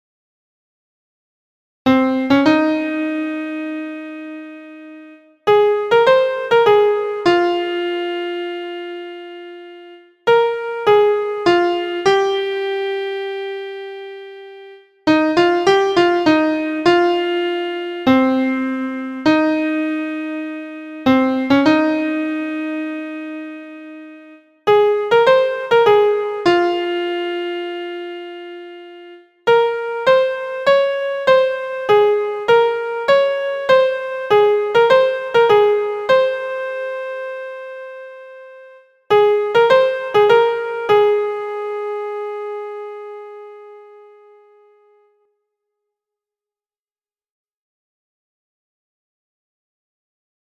Dotted and straight syncopation, melodic rhythm patterns,
four minor thirds (m3) and three perfect fourths (p4).
• Key: A Flat Major
• Time: 4/4
• Form: phrases: ABACD – chorus: AB